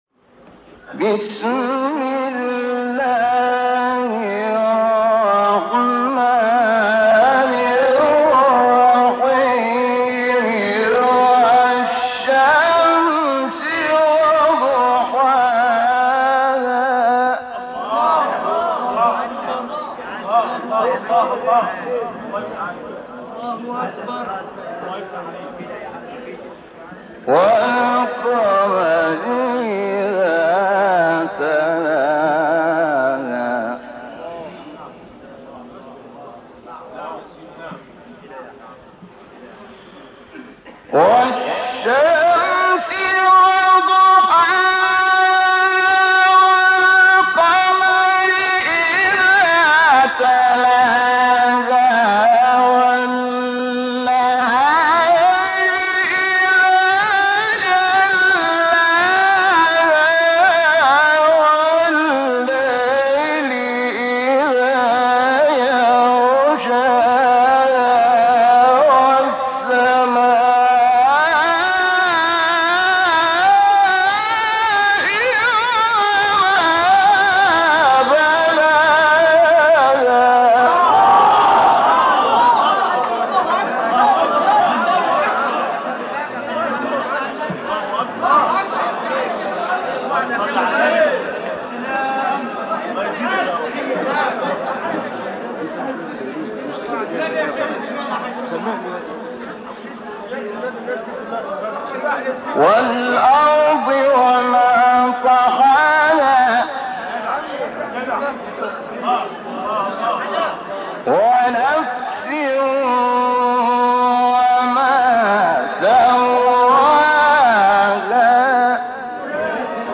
تلاوت بسیار زیبای سورۀ شمس استاد شحات انور | نغمات قرآن
سوره : شمس آیه: تمام سوره استاد : شحات محمد انور مقام : حجاز قبلی بعدی